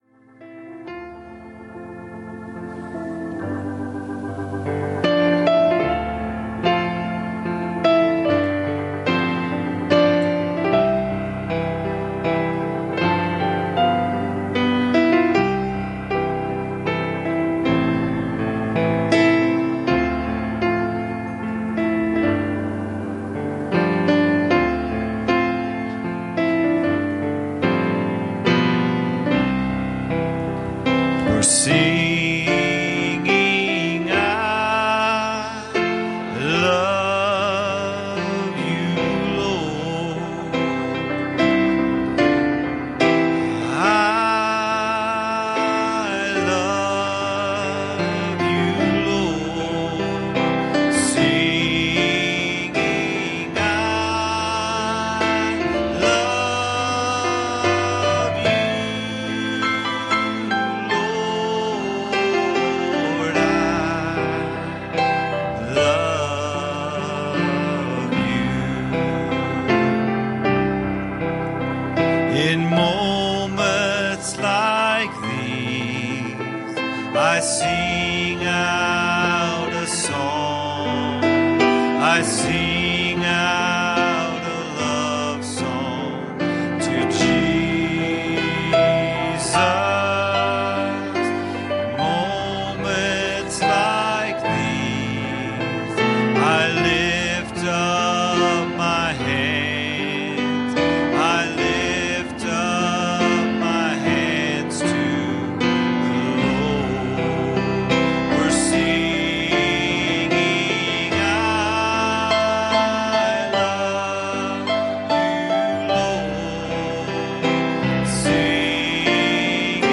Series: Sunday Evening Services Passage: Philippians 2:5 Service Type: Sunday Evening "I will grant you this.